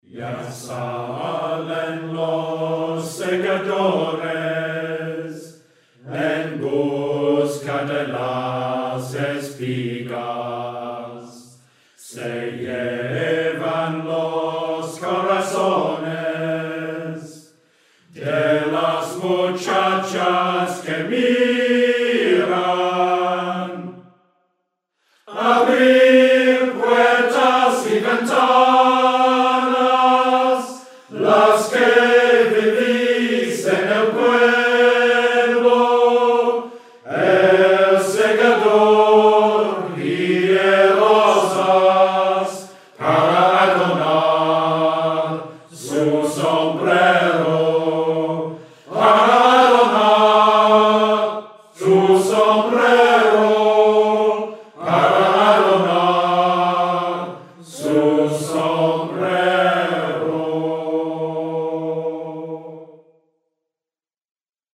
The choir was made up of members of the Richmond Shakespeare Society and Marble Hill Singers, and was recorded on 1st Sep 2007.
Choral (MP3 - 1 min 4 secs - 0.98 Mb)
segadores-choir.mp3